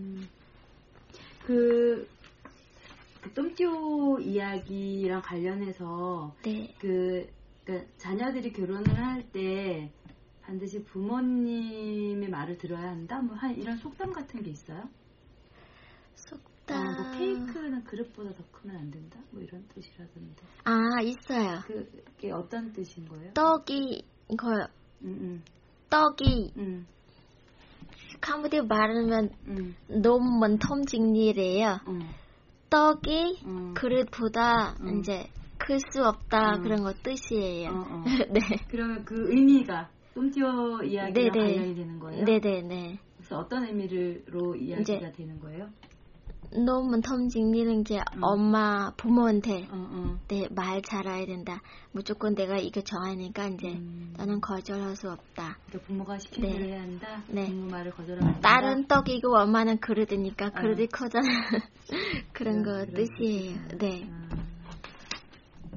이야기분류속담
식사 중에 캄퐁참 지역을 중심으로 사담을 나누었다.